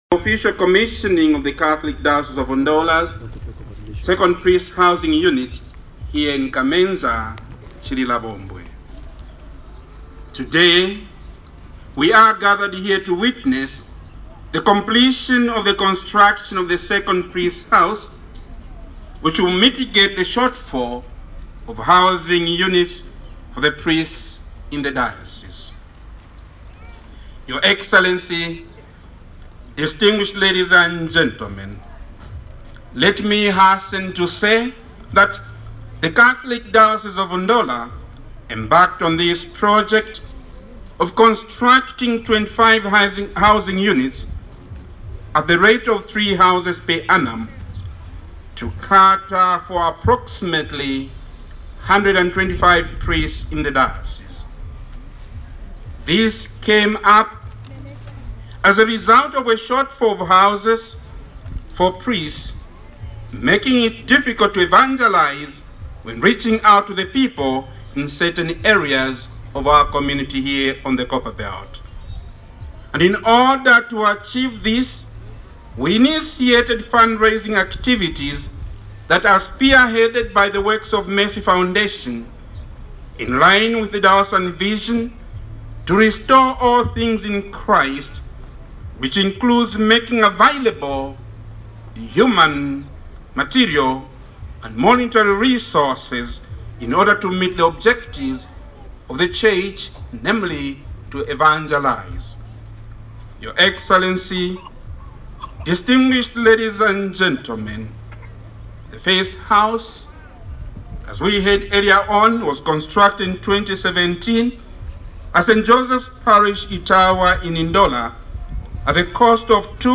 His Grace Archbishop Dr. Alick Banda’s Speech